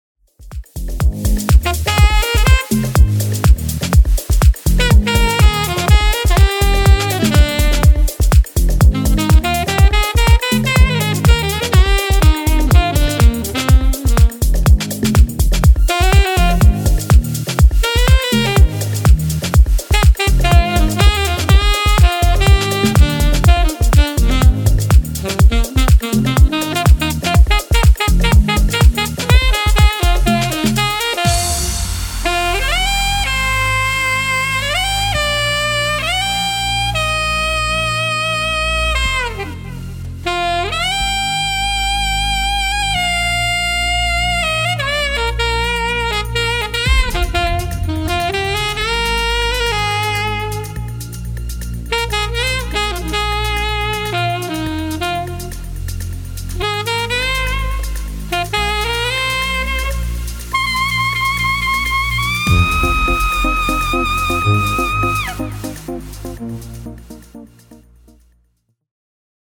Saxophone Player Hire
soulful and vibrant saxophone performer